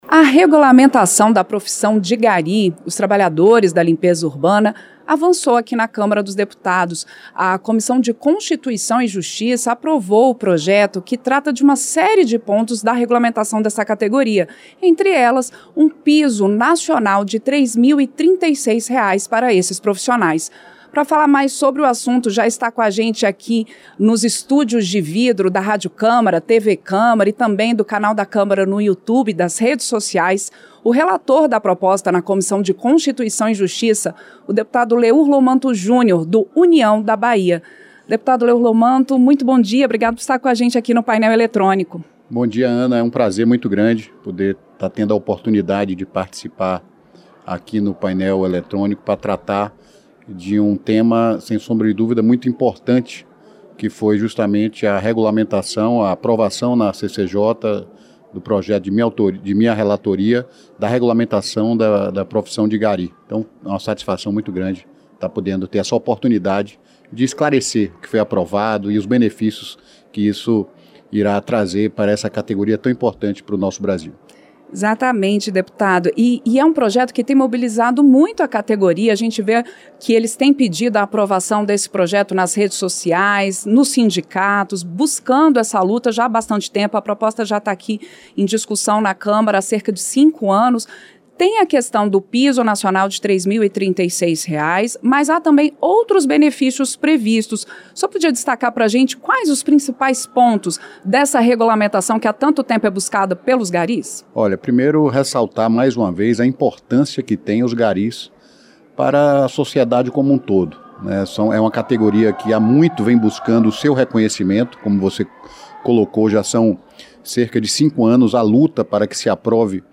Entrevista - Dep. Leur Lomanto Júnior (União-BA)